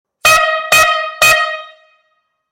Buzinas a Ar para Bicicletas Dupla
• Intensidade sonora 130db;
• Acionamento através de bomba manual;
Som da Buzina